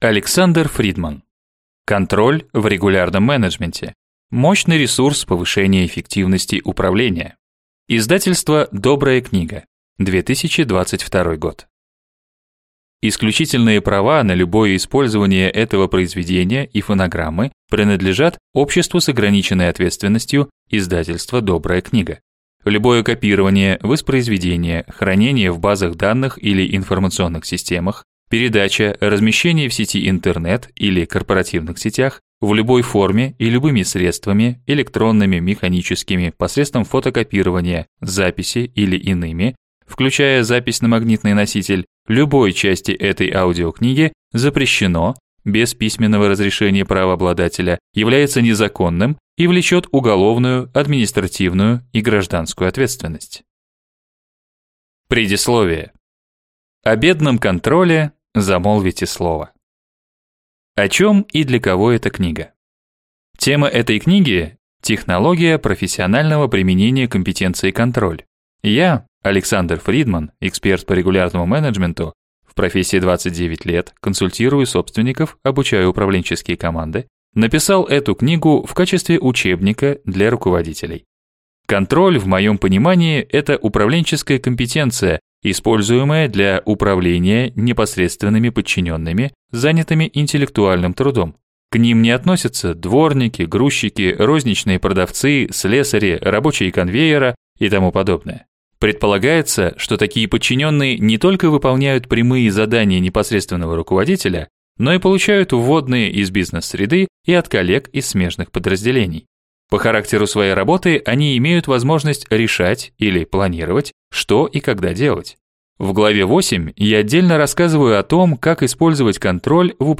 Аудиокнига Контроль в регулярном менеджменте: мощный ресурс повышения эффективности управления | Библиотека аудиокниг